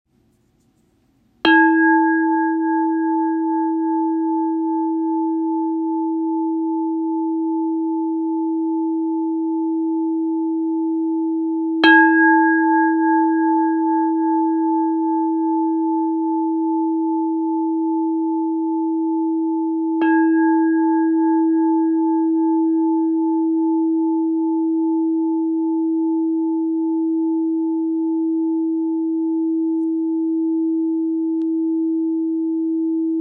Zpívající křišťálová mísa
Křišťálová mísa Duch PLAMEŇÁKA - tón E, 3. čakra solar plexus
432 Hz
Křišťálová mísa - tón E